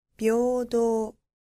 • びょうどう
• byoudou